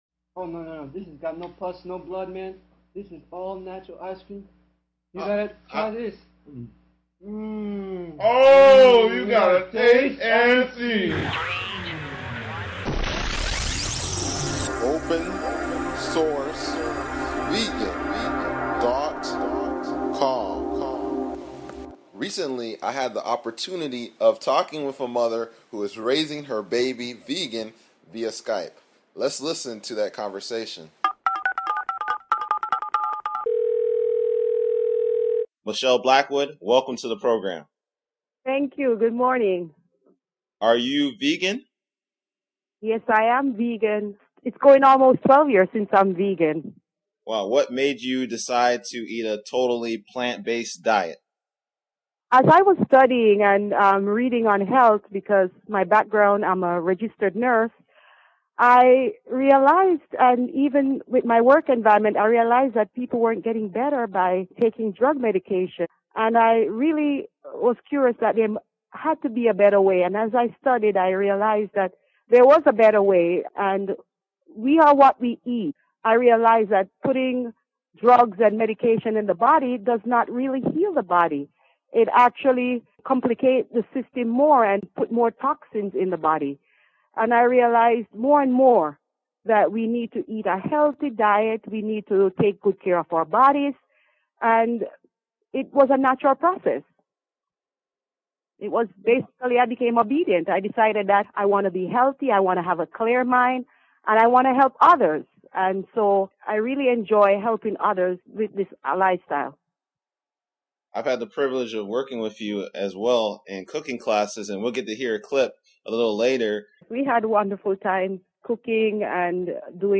Interview with the Mother of a One Year Old Vegan Baby